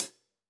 Index of /musicradar/Hi Hats/Zildjian K Hats
KHats Clsd-01.wav